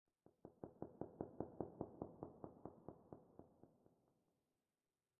Minecraft Version Minecraft Version latest Latest Release | Latest Snapshot latest / assets / minecraft / sounds / ambient / underwater / additions / crackles1.ogg Compare With Compare With Latest Release | Latest Snapshot
crackles1.ogg